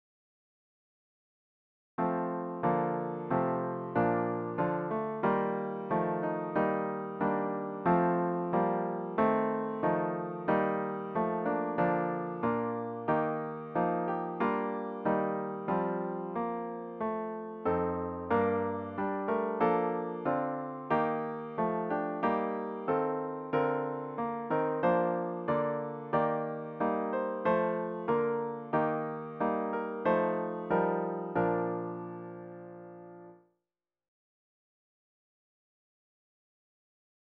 The hymn should be performed at a stately♩= ca. 90.